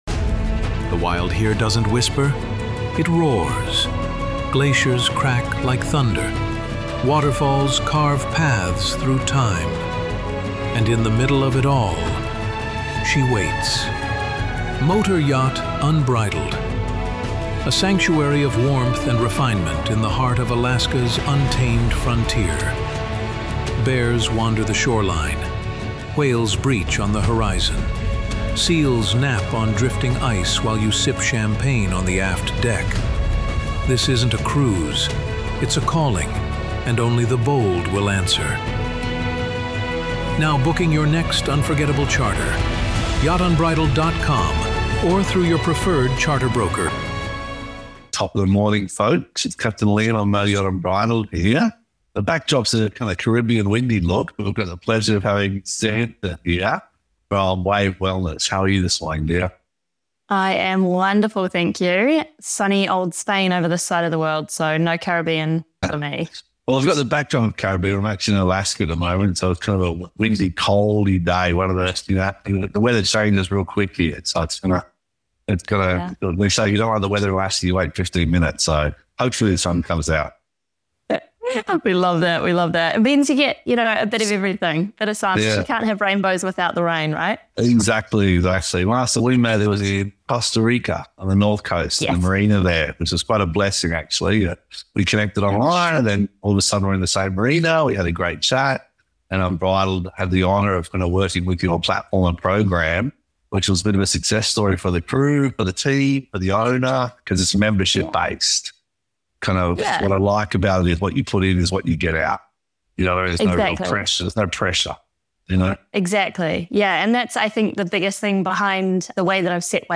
raw and honest conversation